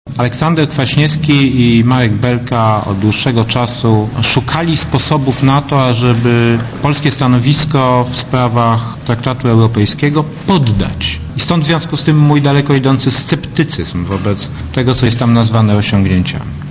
Rokita podkreślił na konferencji prasowej w Gdańsku, że nie rozumie, dlaczego Polska uległa w kwestii odwołania do wartości chrześcijańskich.